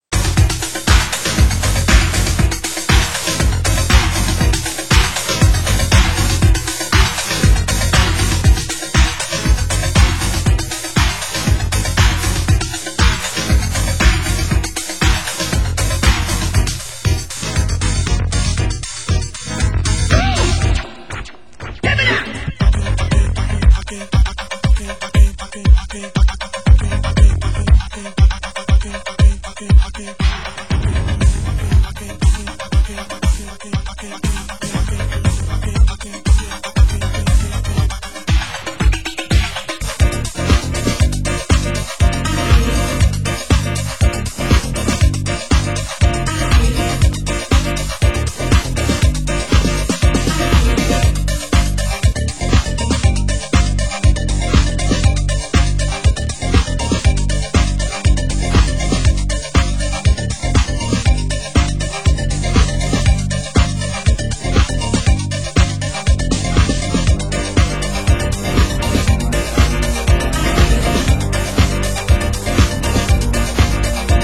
Genre UK House